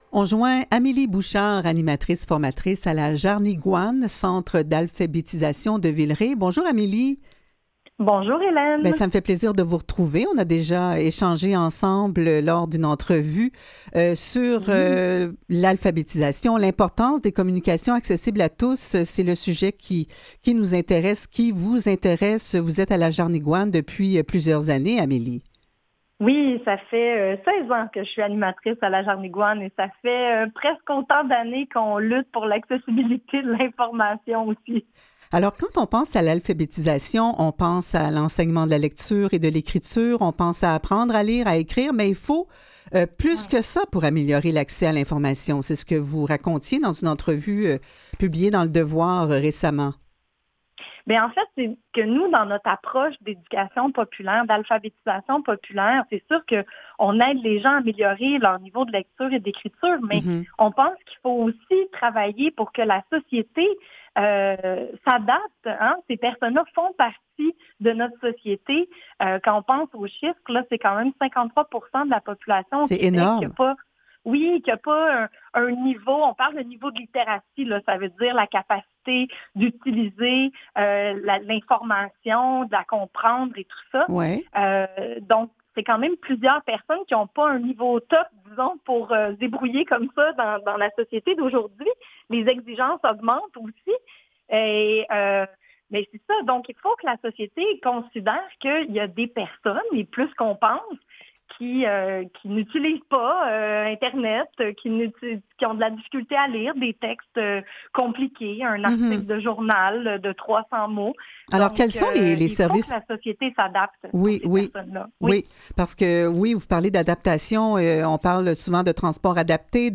Revue de presse et entrevues du 22 septembre 2021